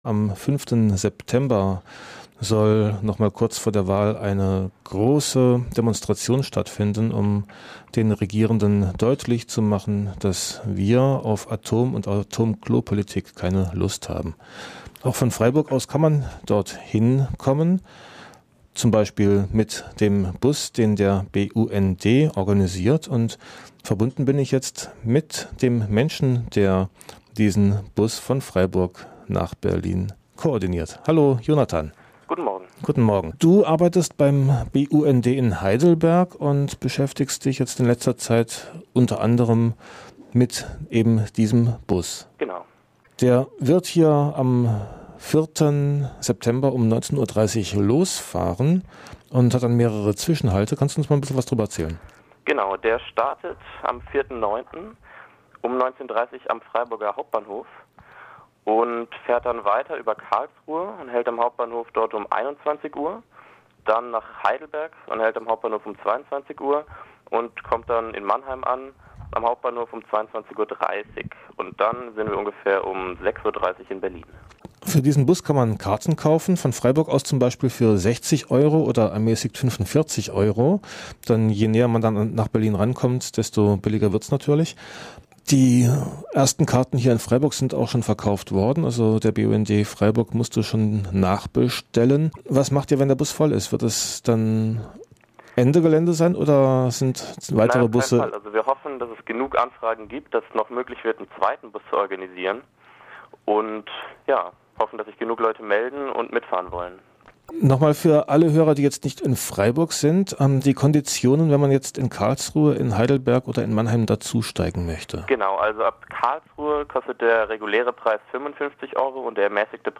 Telefonat